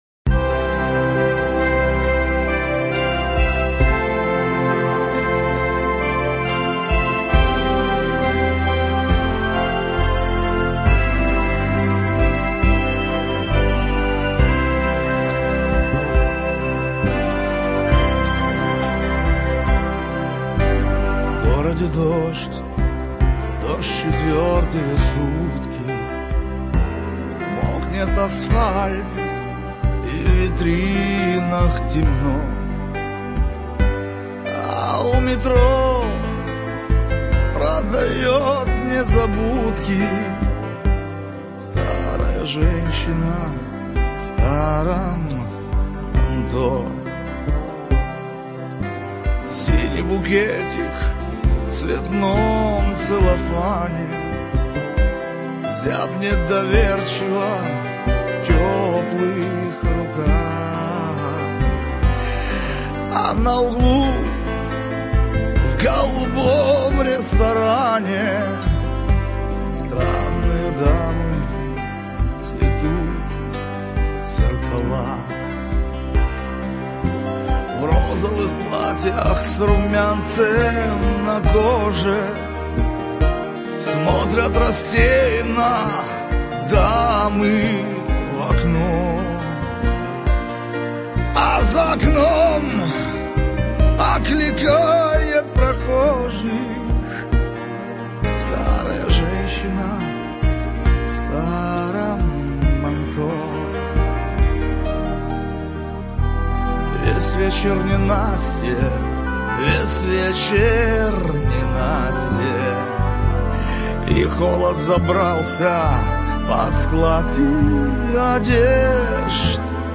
Эстрада, шансон